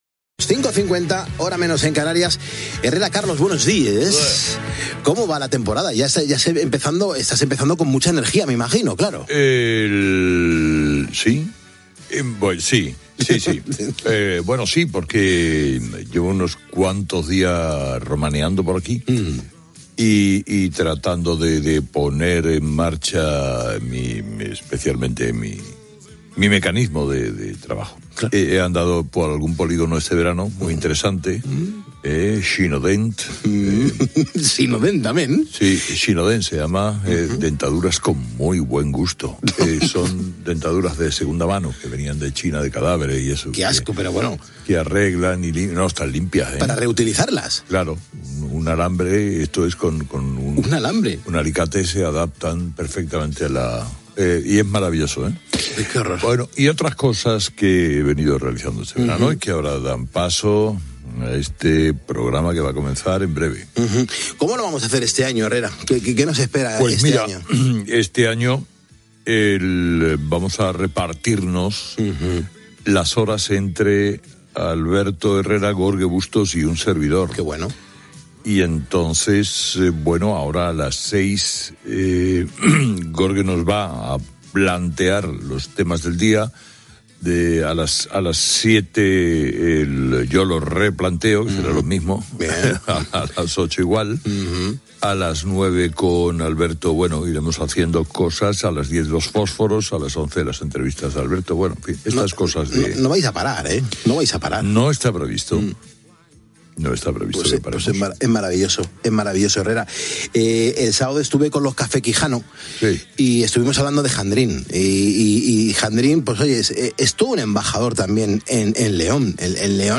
Conversa amb Carlos Herrera, qui explica com es realitzarà el programa "Herrera en COPE" de la temporada 2025-2026.